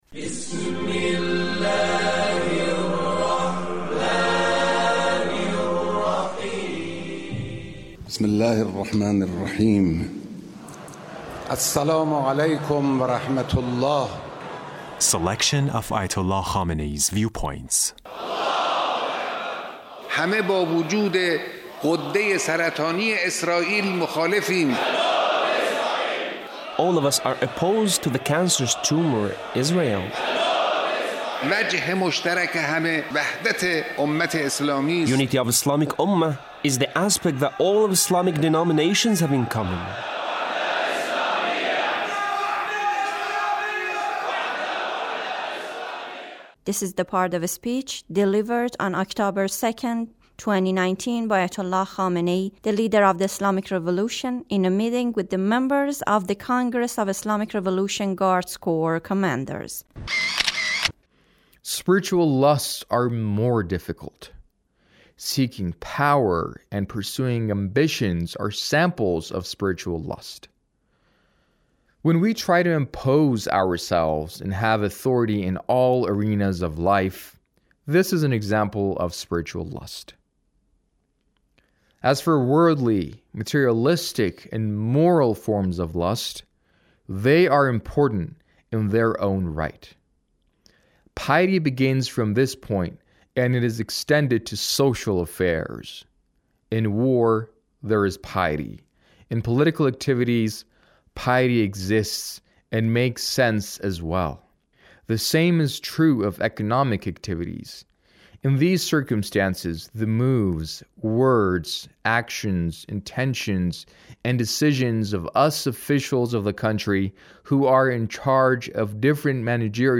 Leader's Speech (6)